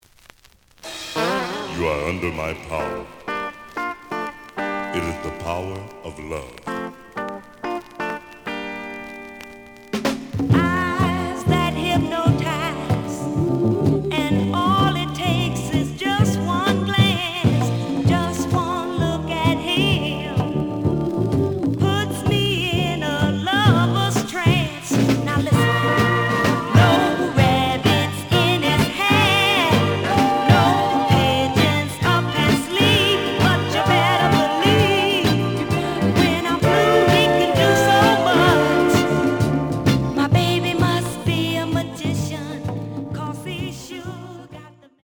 試聴は実際のレコードから録音しています。
●Genre: Soul, 60's Soul
傷は多いが、プレイはまずまず。)